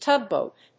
• / ˈtʌˌgbot(米国英語)
• / ˈtʌˌgbəʊt(英国英語)